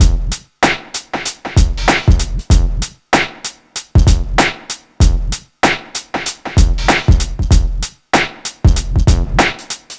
Free breakbeat - kick tuned to the F note. Loudest frequency: 2552Hz
• 96 Bpm Drum Groove F Key.wav
96-bpm-drum-groove-f-key-QvO.wav